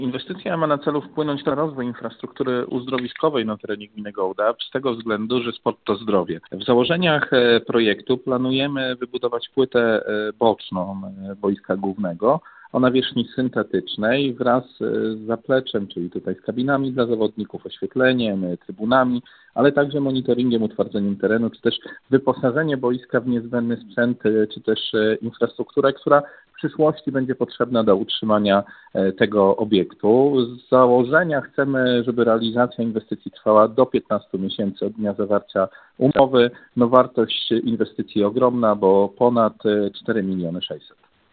– Z nowego obiektu będzie można korzystać w czerwcu przyszłego roku, mówi Radiu 5 włodarz Gołdapi.